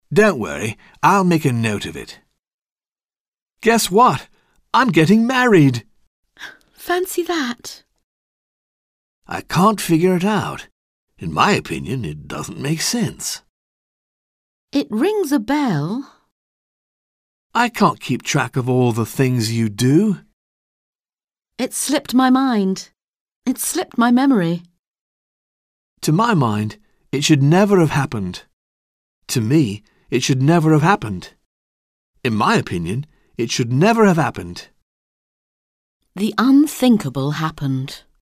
Un peu de conversation - Penser, se souvenir